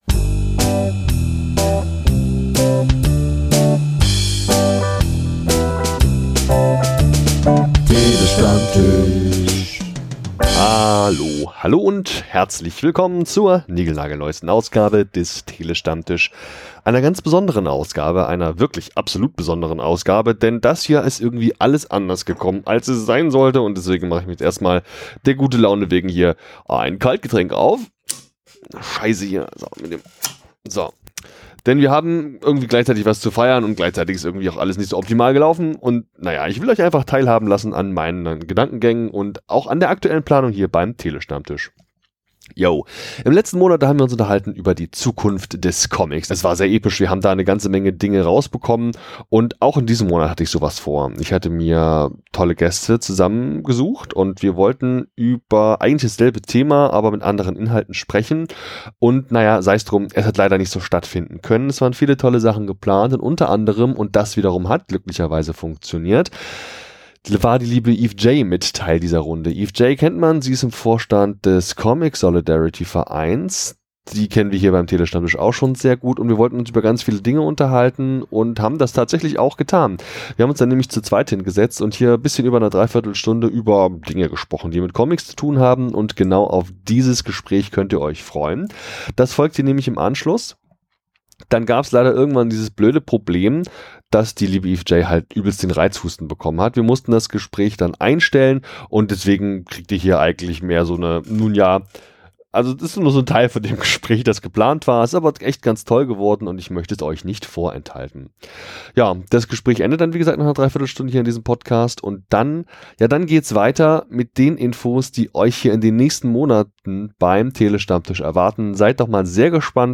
Short Crowd Cheer 2.flac